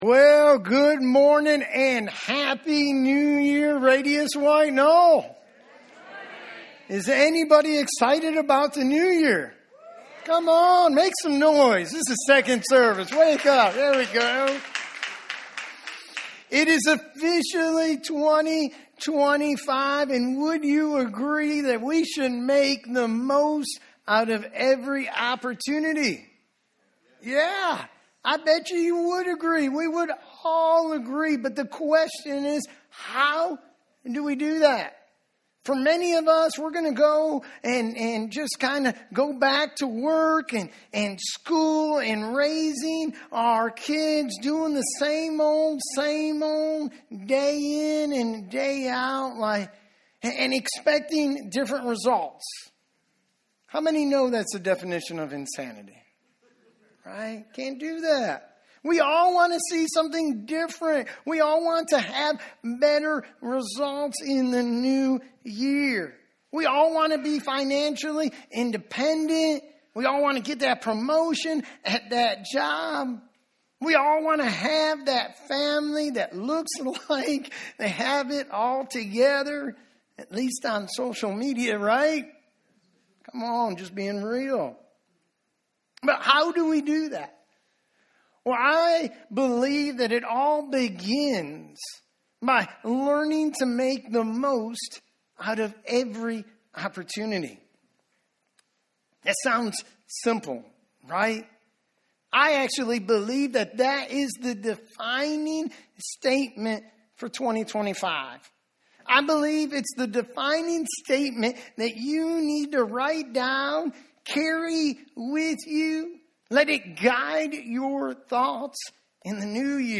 From Campus: "RADIUS White Knoll"